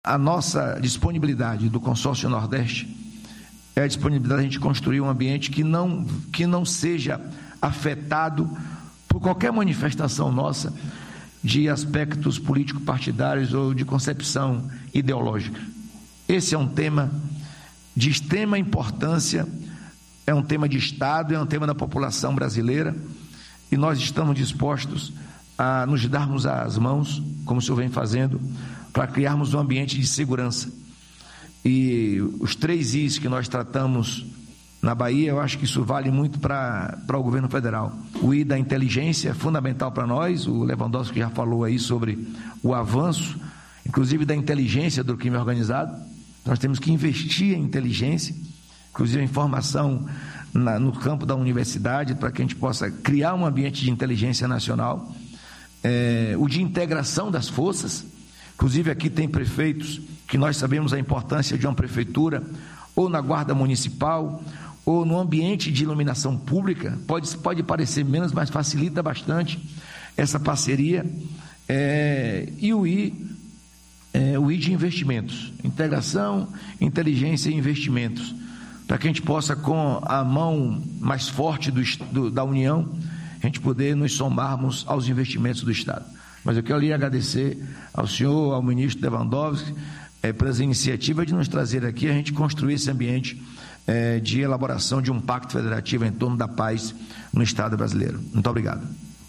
🎙Jerônimo Rodrigues – Governador da Bahia